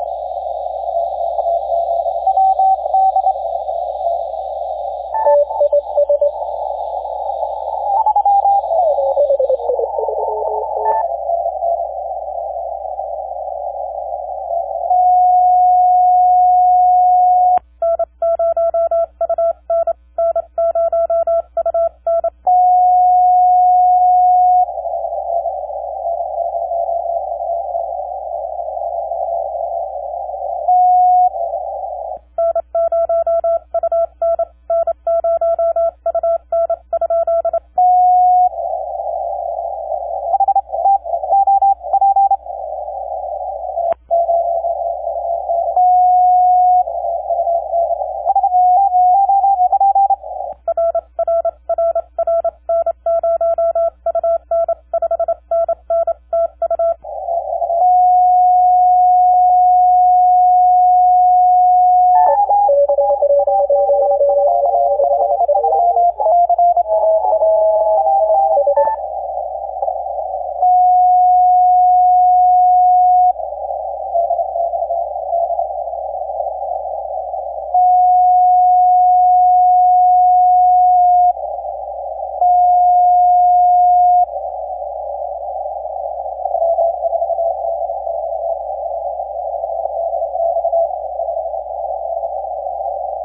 3Y0K 40M CW = Hard Work
Sunrise on Bouvet was 0612 their local time this morning (0512Z). It took some work getting through the idiot tuners and frequency police but I bagged them 4 minutes after their sunrise.